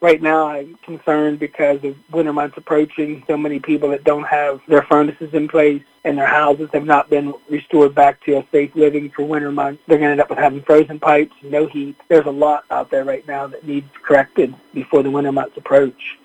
With still no response from FEMA regarding relief funds for the Western Maryland May 13th flood, Mayor Jack Coburn of Lonaconing told WCBC he is unsure how citizens are going to receive help.